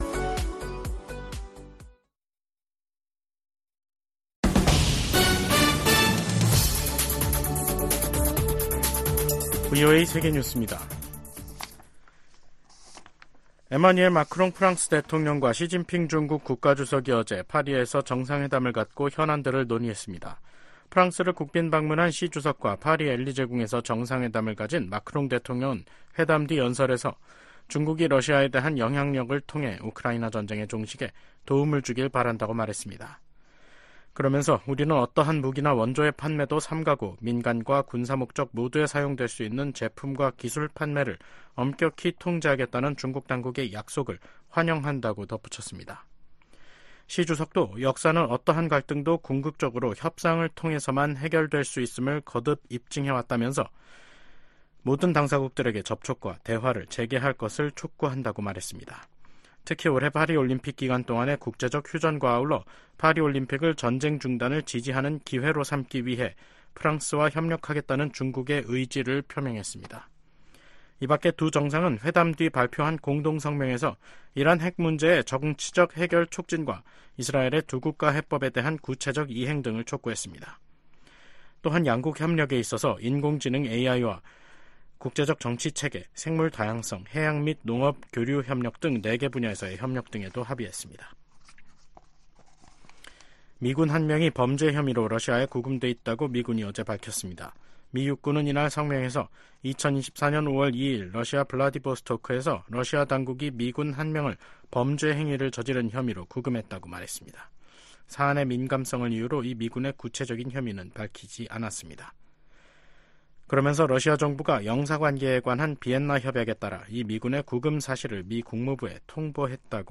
VOA 한국어 간판 뉴스 프로그램 '뉴스 투데이', 2024년 5월 7일 3부 방송입니다. 백악관이 유엔이 정한 연간 한도를 초과한 대북 정제유 공급과 관련해 제재를 이행하지 않고 있는 나라가 있다고 비판했습니다. 국방부가 6일 북한의 로켓 엔진시험 정황과 관련해 한국과 일본에 대한 굳건한 방위 공약을 재확인했습니다. 미국과 한국 일본, 유럽국가들이 러시아의 유엔 안보리 거부권 남용을 비판했습니다.